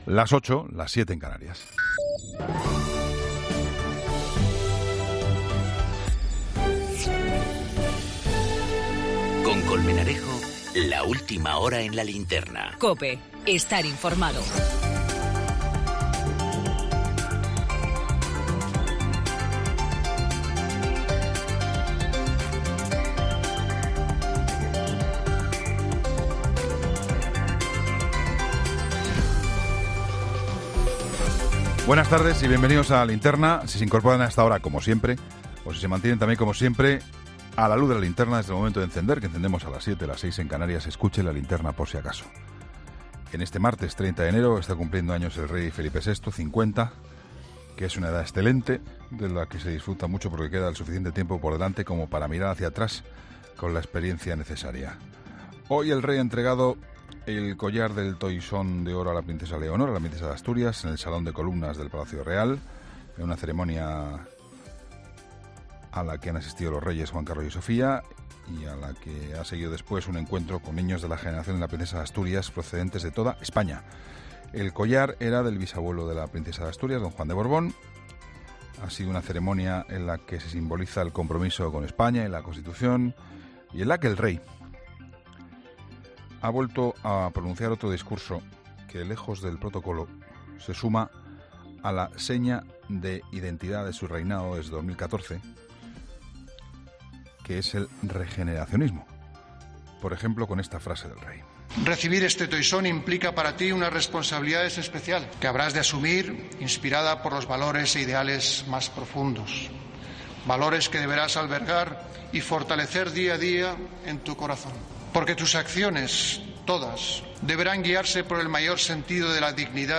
El director de ‘La Linterna’ se ha referido en su editorial a la decisión del presidente del Parlamento catalán de aplazar el pleno de investidura de Puigdemont hasta que haya garantías de que pueda ser investido